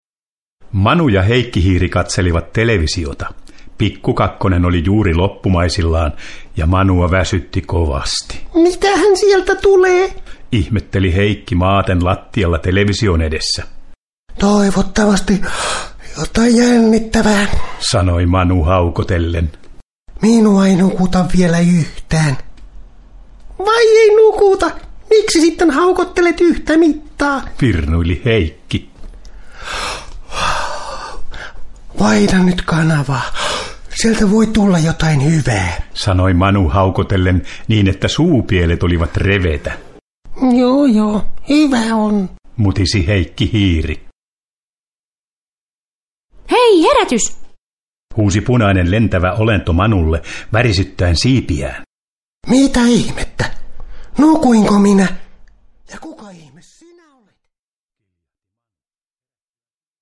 Miina ja Manu avaruudessa – Ljudbok – Laddas ner